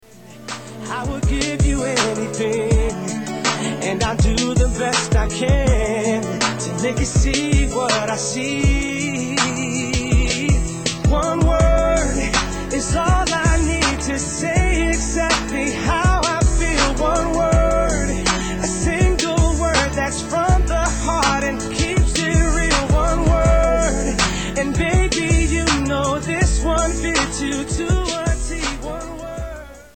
R & B Pop